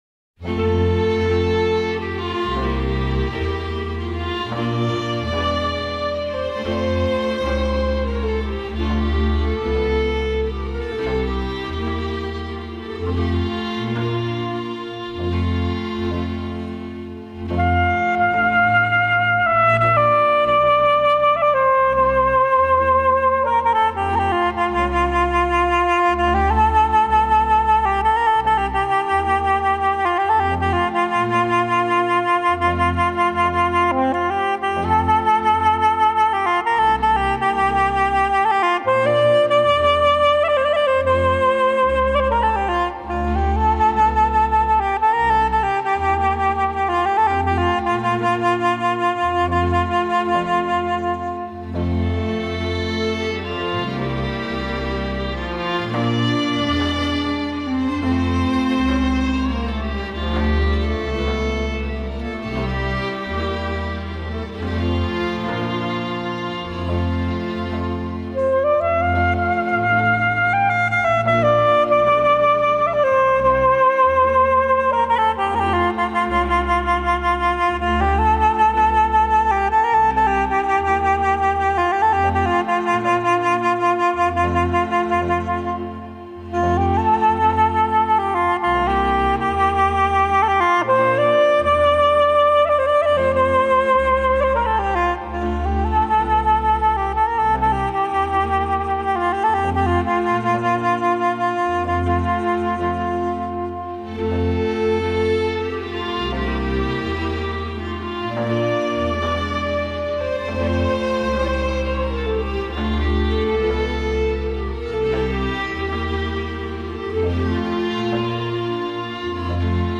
cu acompaniamentul orchestrei